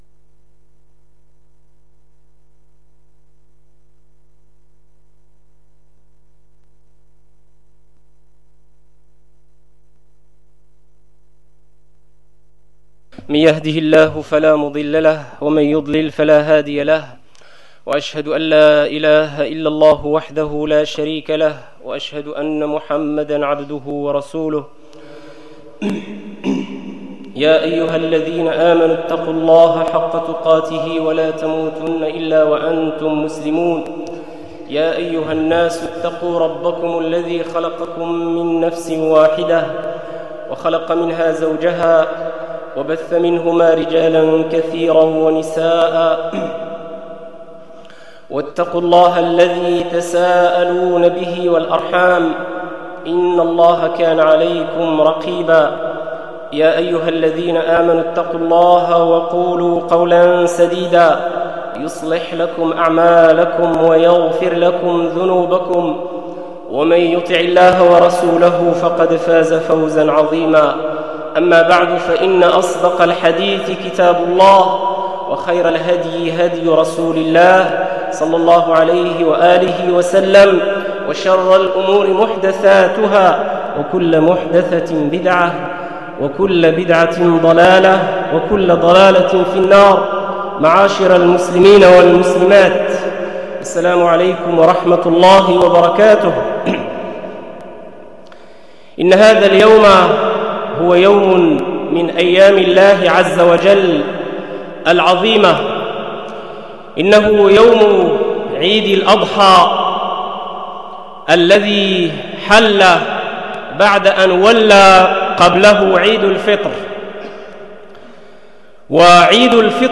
Possibilités d'écouter en ligne des conférences sur les trois domaines précités: